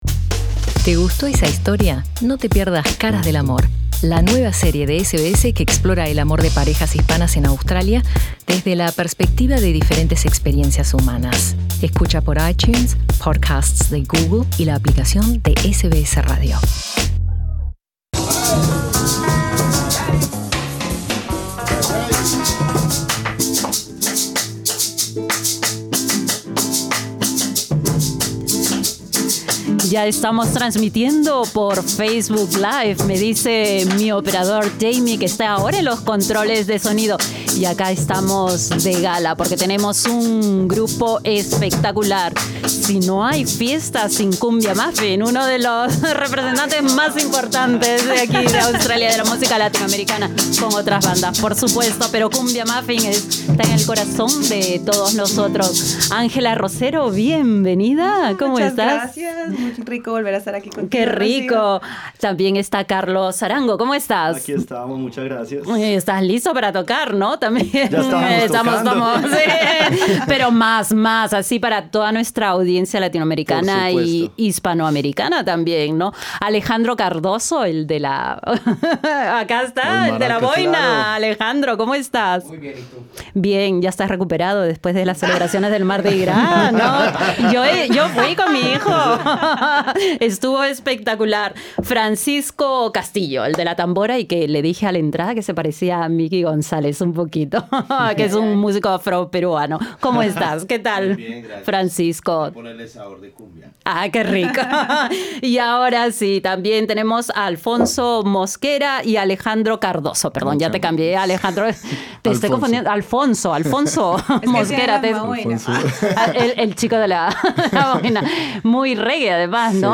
un repertorio de cumbia y otros ritmos modernos